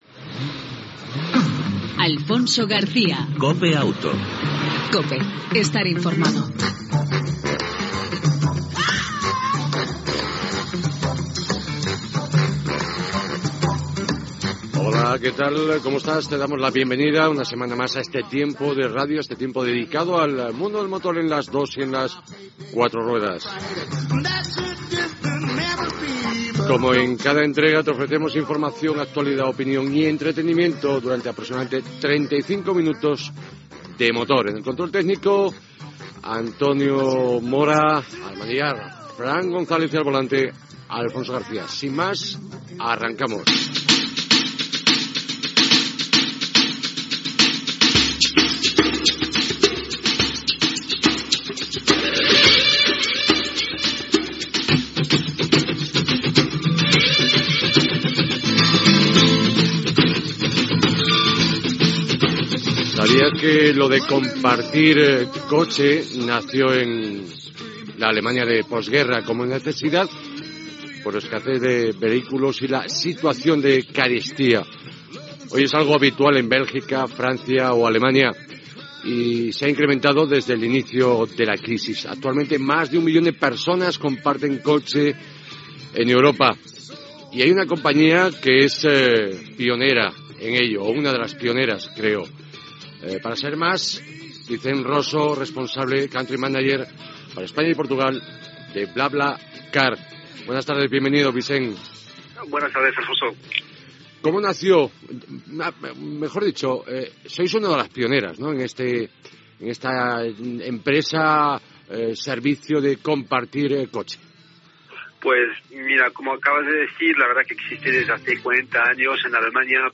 Divulgació
FM